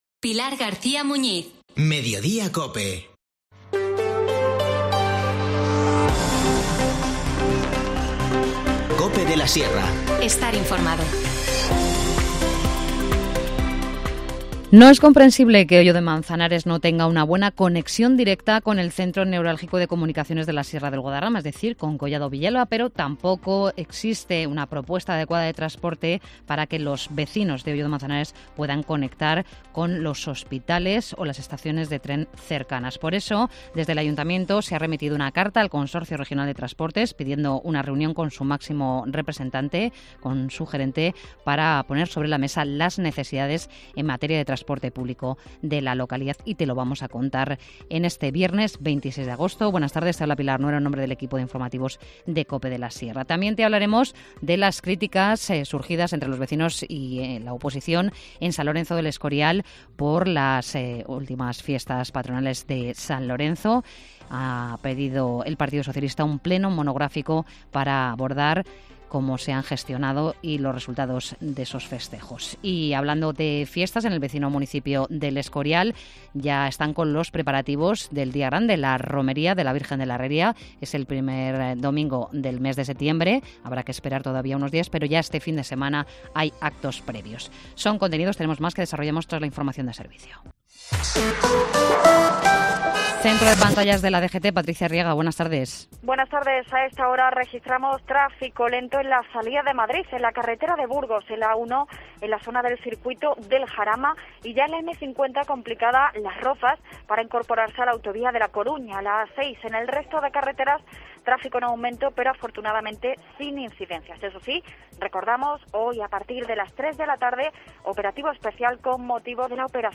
Informativo Mediodía 26 agosto